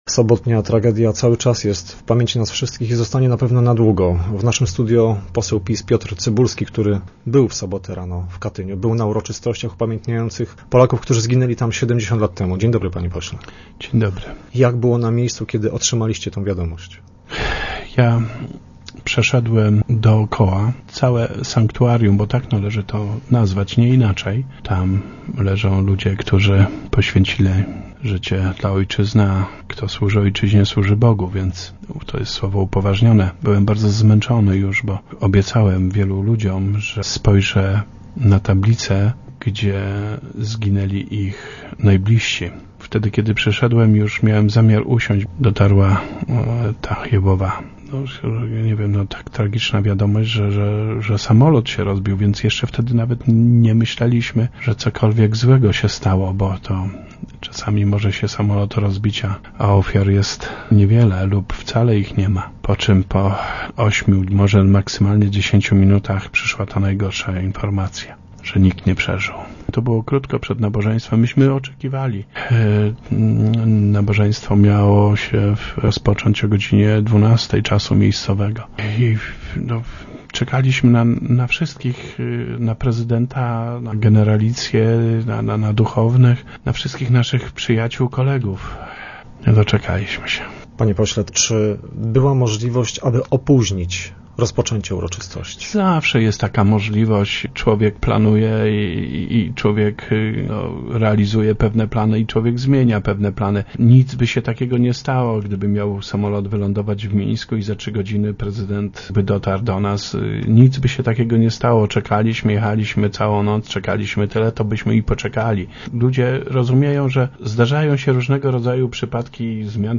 Poseł PiS Piotr Cybulski nie może pogodzić się z tym co zaszło. W naszym studiu łkał na wspomnienie tej tragedii.